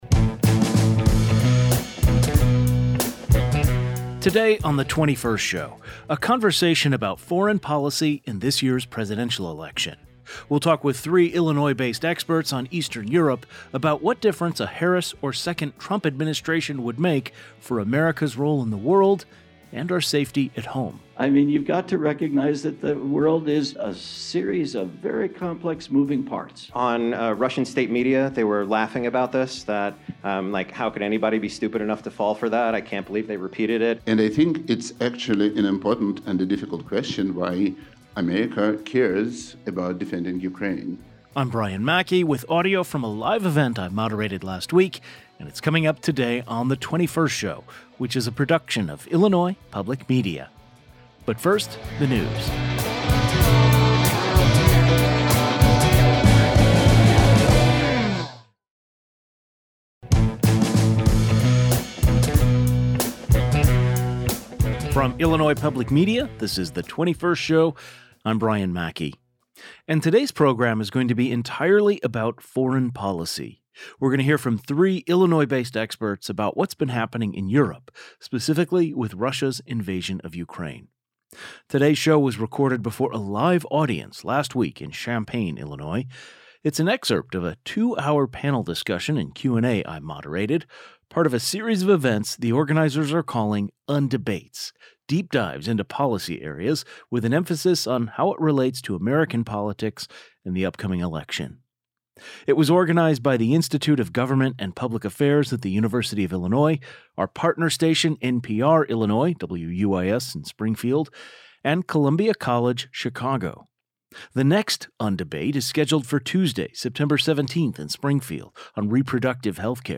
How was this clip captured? Today’s show was recorded before a live audience last week in Champaign, Illinois. It’s an excerpt of a two-hour panel discussion and part of a series of events called “Undebates” — deep dives into policy areas, with an emphasis on how it relates to American politics and the upcoming election.